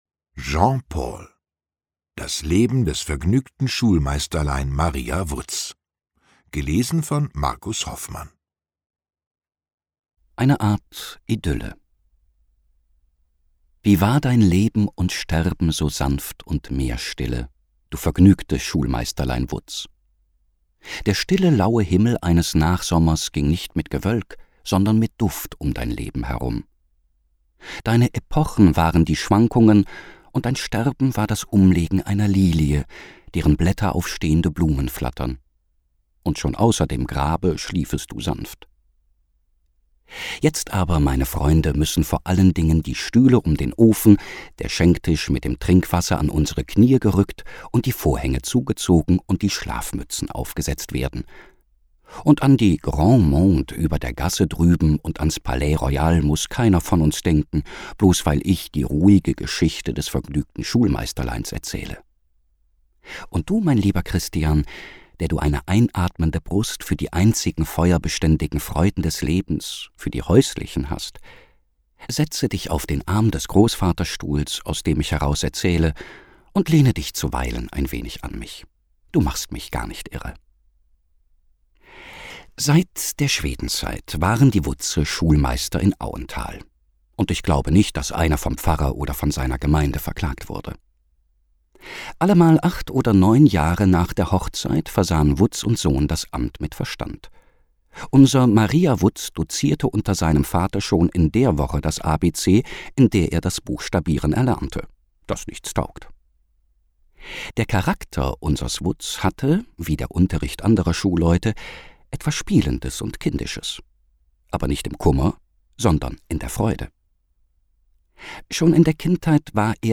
»Die Hörbuch-Edition ›Große Werke. Große Stimmen.‹ umfasst herausragende Lesungen deutschsprachiger Sprecherinnen und Sprecher, die in den Archiven der Rundfunkanstalten schlummern.« SAARLÄNDISCHER RUNDFUNK